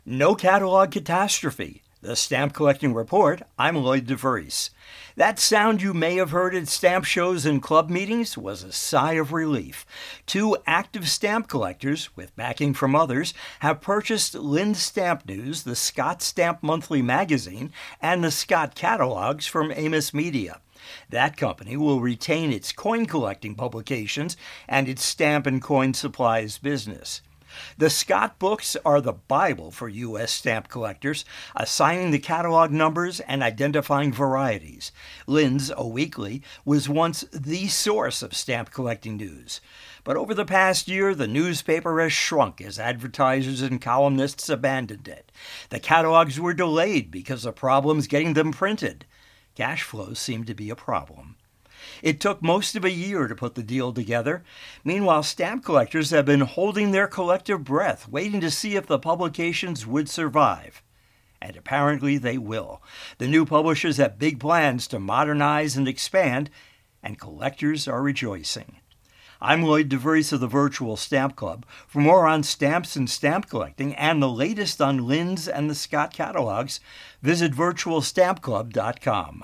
A radio feature